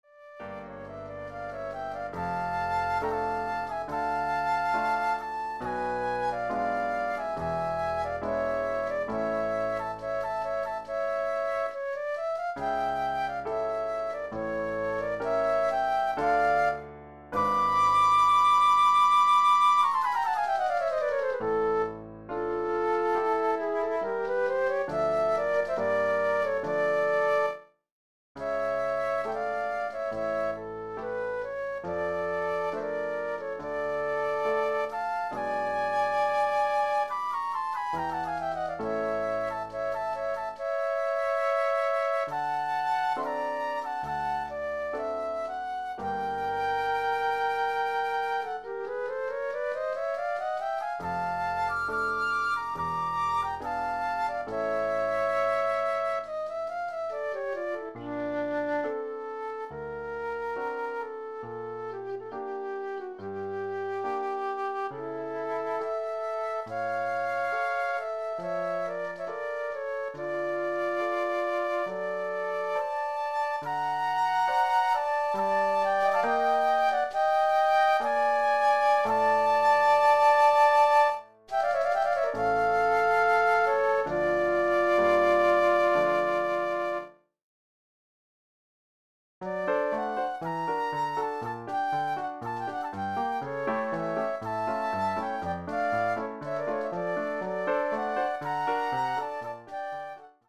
Pour flûte ou deux flûtes et piano
(Son numérique)